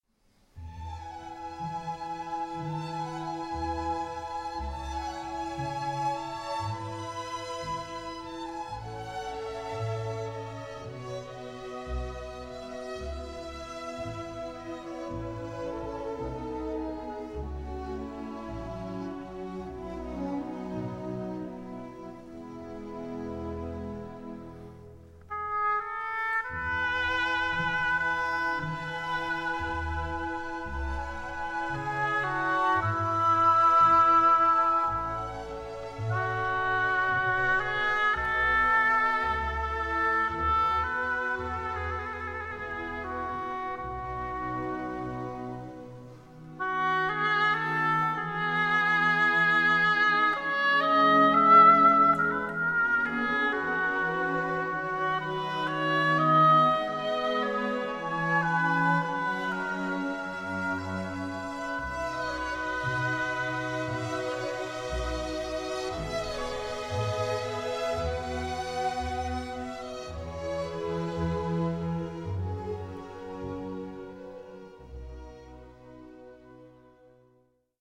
Städtekonzert Münster
Albrecht Mayer, Oboe